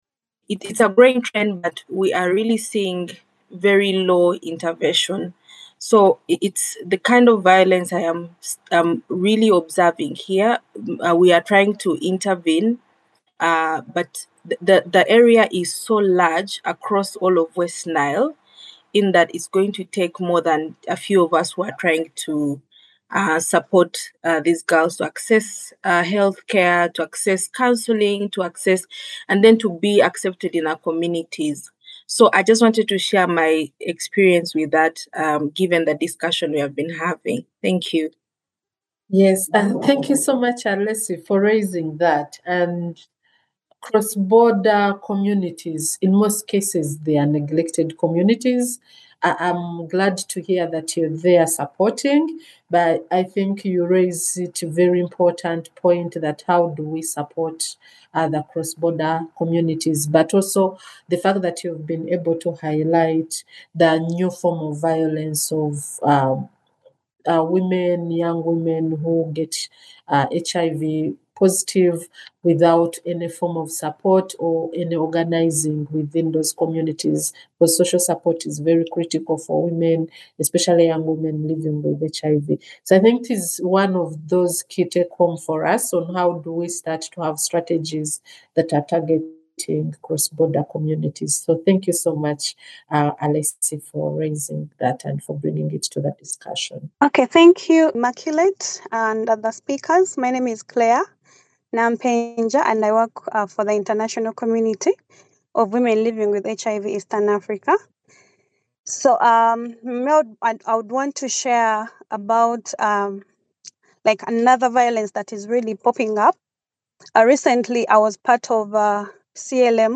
Webinar: Shifts in Ending New and Evolving Forms of Violence Part 6 – International Community of Women living with HIV Eastern Africa
Listen to Part Six conversation below;